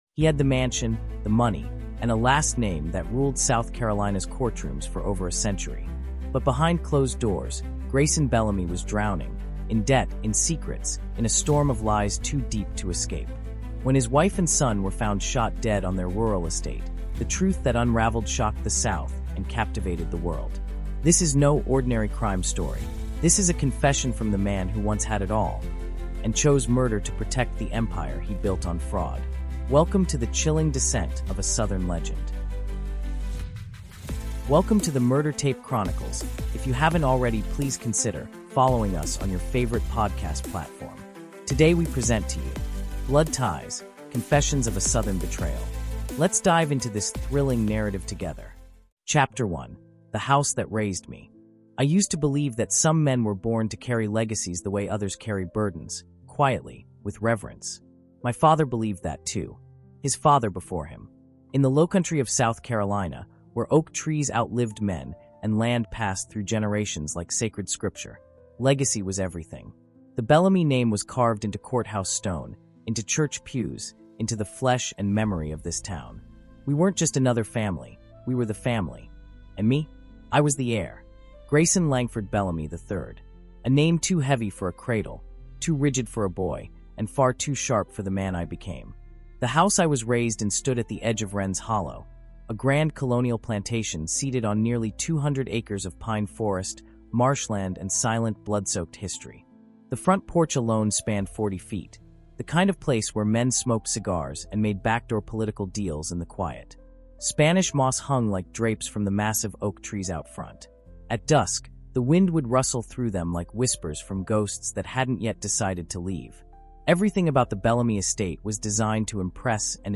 Blood Ties: Confessions Of A Southern Betrayal | Audiobook